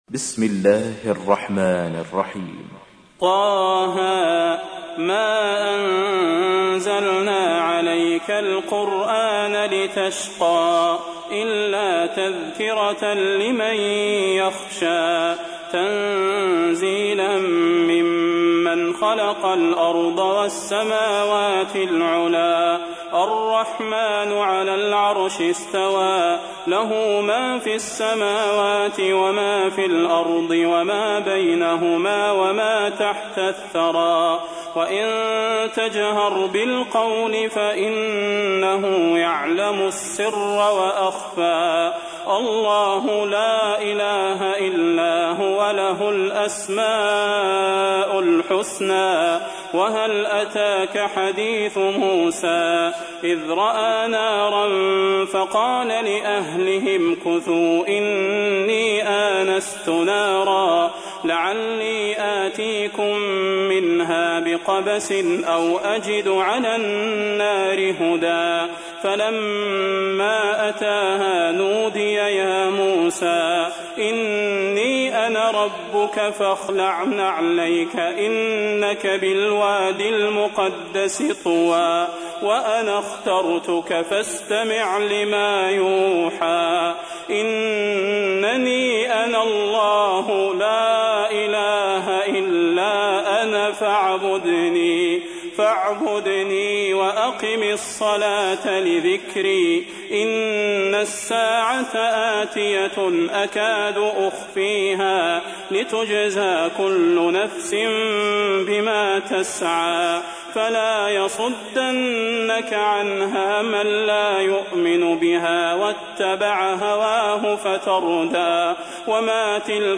تحميل : 20. سورة طه / القارئ صلاح البدير / القرآن الكريم / موقع يا حسين